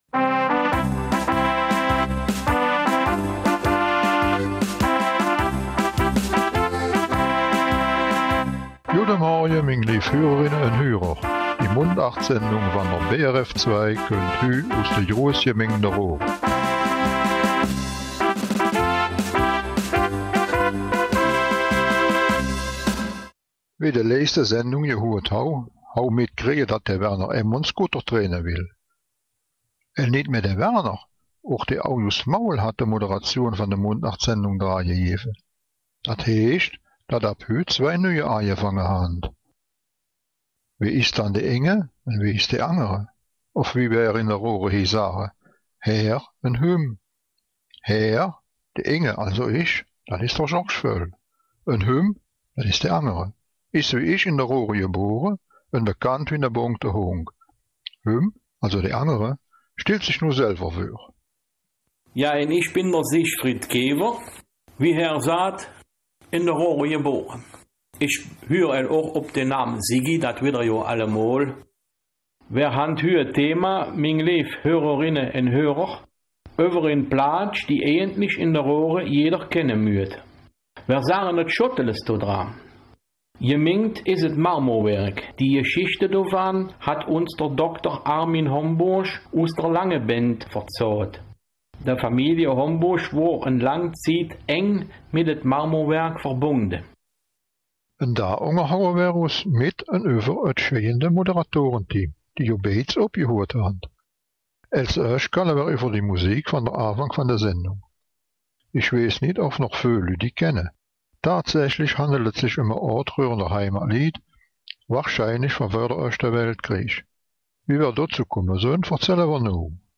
Raerener Mundart: Stabwechsel bei den Moderatoren